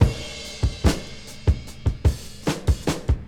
• 73 Bpm HQ Breakbeat Sample B Key.wav
Free drum groove - kick tuned to the B note. Loudest frequency: 779Hz
73-bpm-hq-breakbeat-sample-b-key-42E.wav